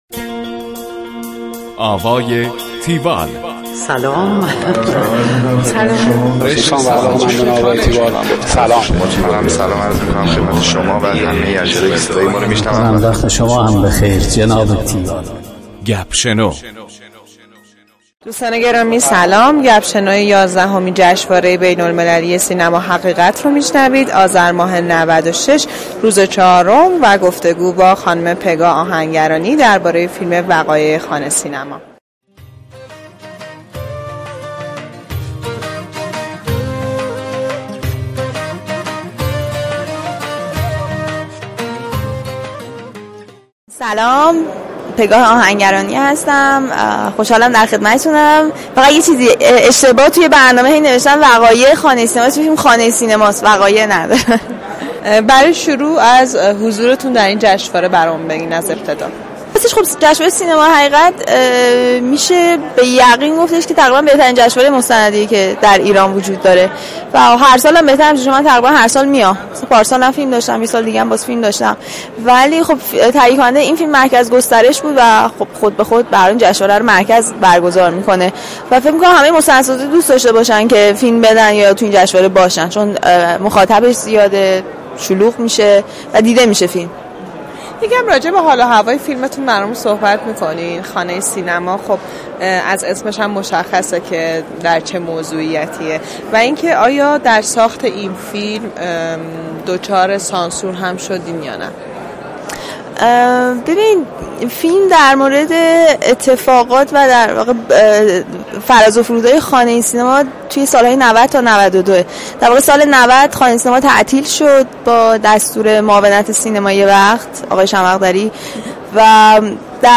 گفتگوی تیوال با پگاه آهنگرانی